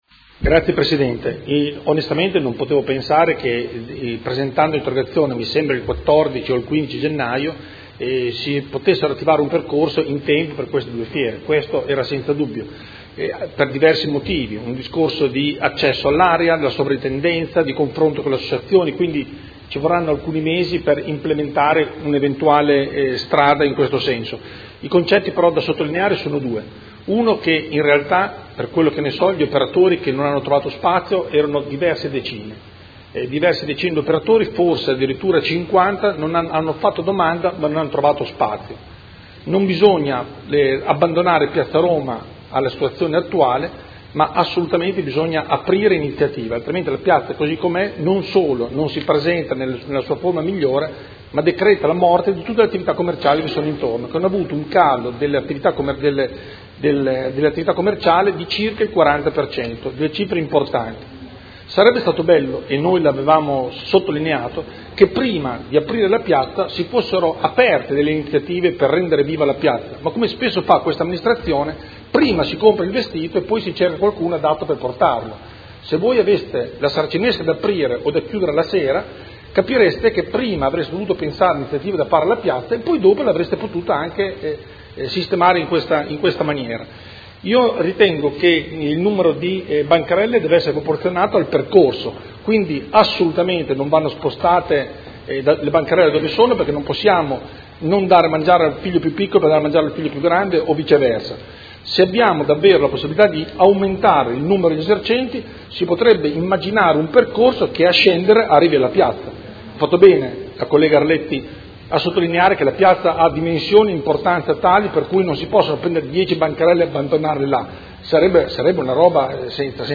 Seduta del 28 gennaio. Interrogazione del Consigliere Galli (F.I.) avente per oggetto: Perché non allargare a Via Farini/Piazza Roma le bancherelle per S. Geminiano?